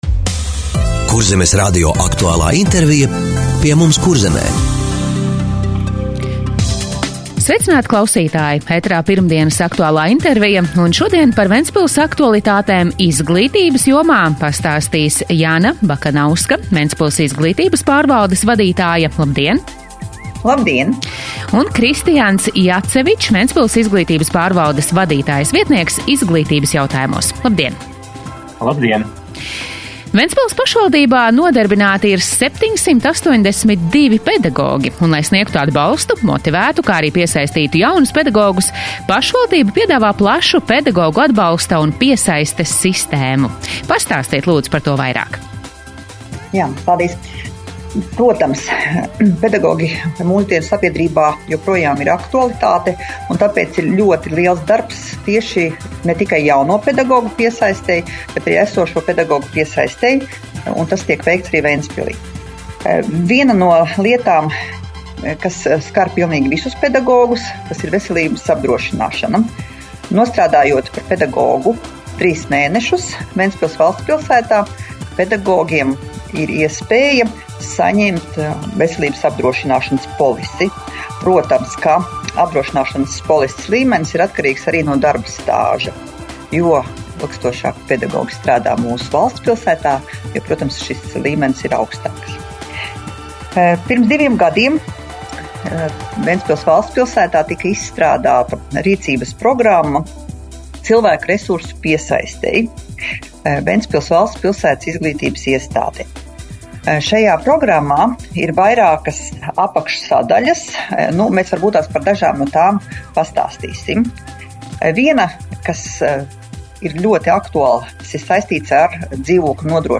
Radio saruna Ventspils Izglītības pārvaldes aktualitātes